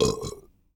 PIANETA GRATIS - Audio Suonerie - Persone - Rutti e Scoregge 01
BURP B    -S.WAV